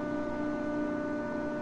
whirr.wav